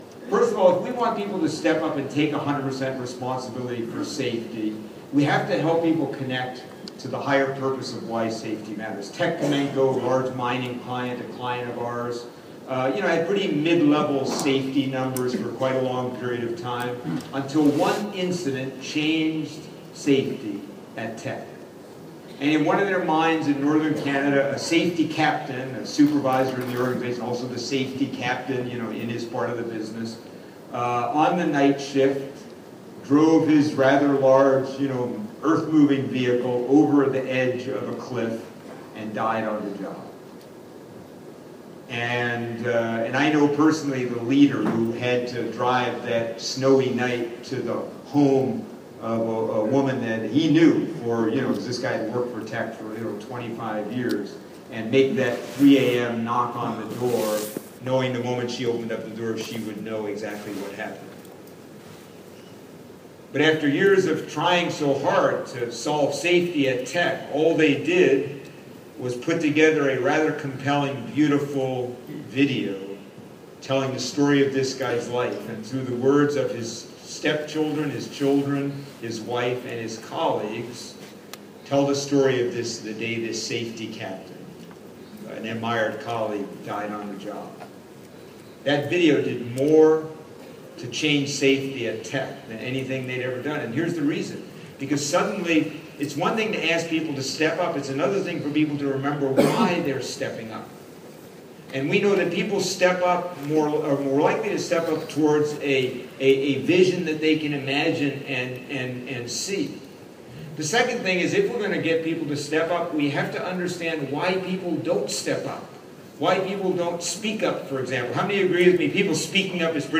Keynote Address